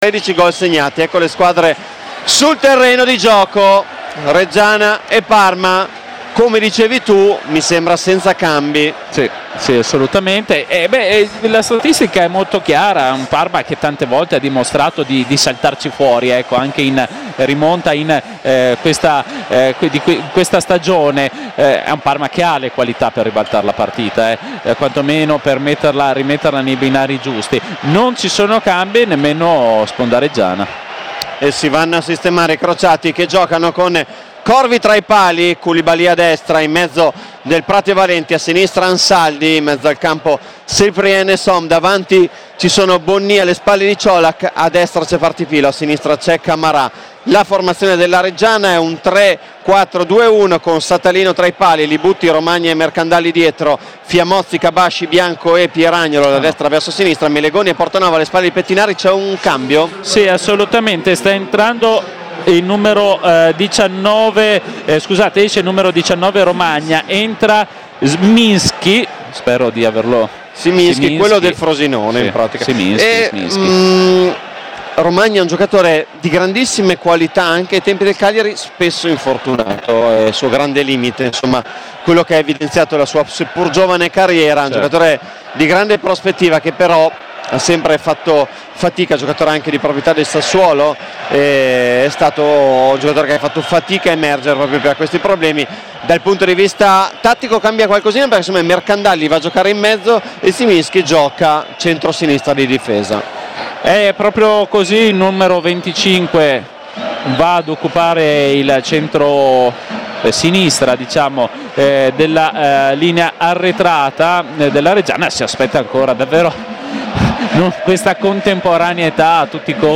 Radiocronaca
Commento tecnico